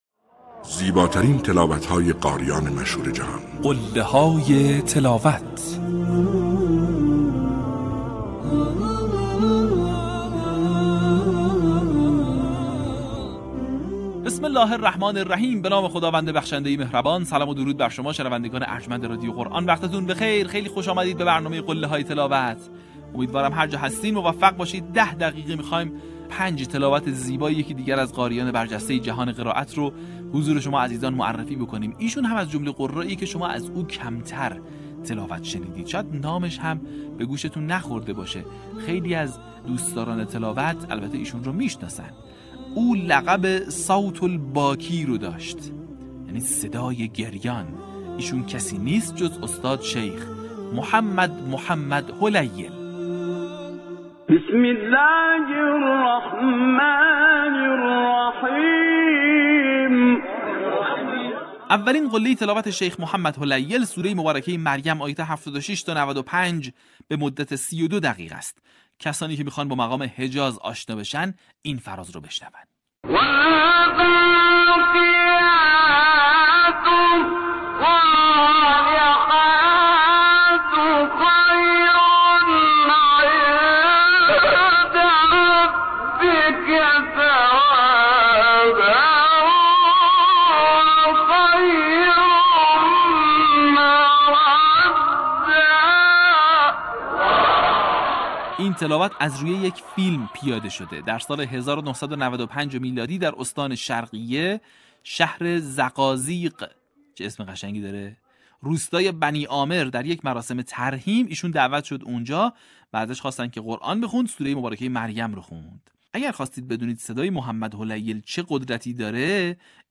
در قسمت چهل‌ونهم فراز‌های شنیدنی از تلاوت‌های به‌یاد ماندنی استاد «محمد هلیل» را می‌شنوید.